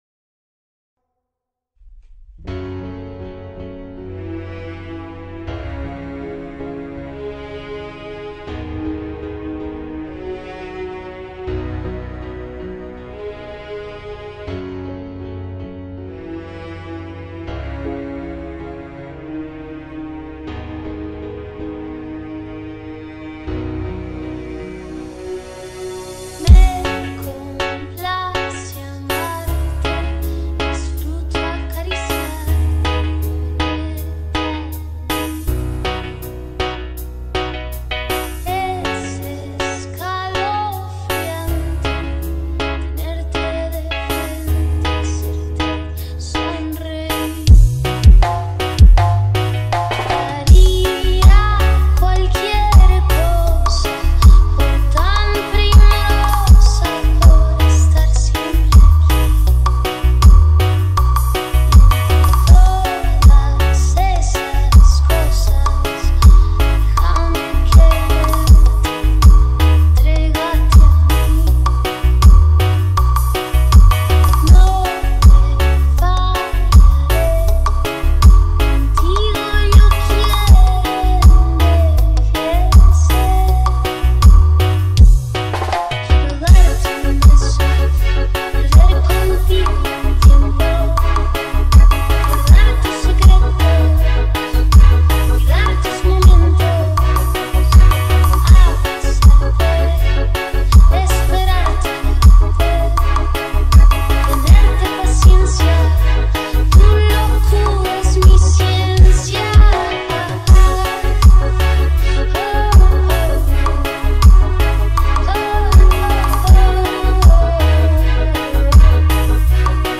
2025-02-12 22:47:22 Gênero: Reggae Views